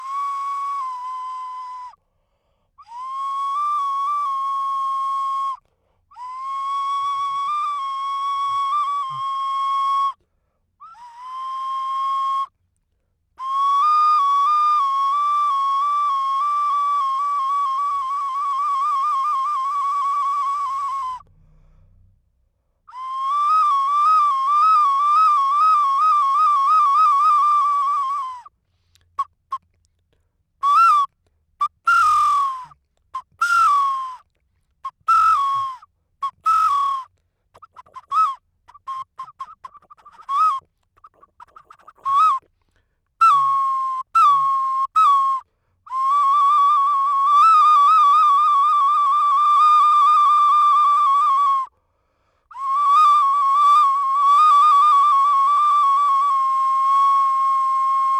Audio 2. Ocarina de cerámica en forma de búho, contexto ritual del Patio 1, Complejo Oeste del Grupo B (fig. 7b).